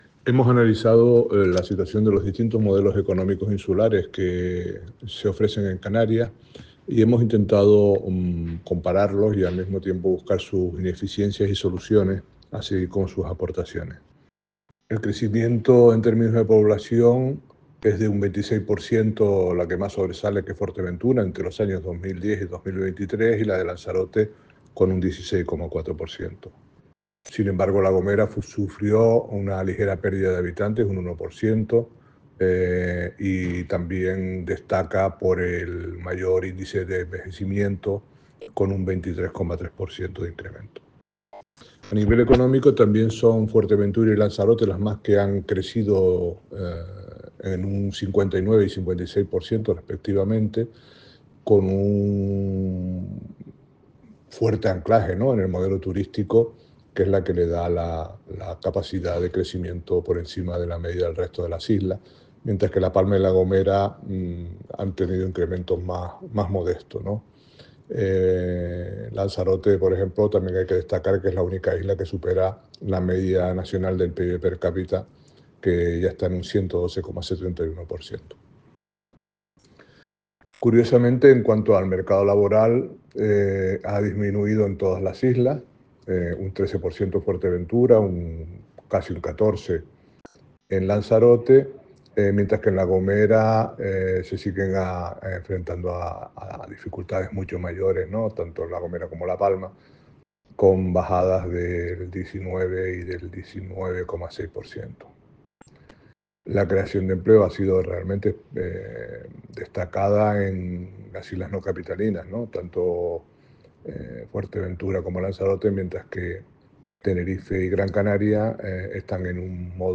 DECLARACION-MONITOR-DE-LA-ECONOMIA-ENERO-2025-mp3cut.net_.mp3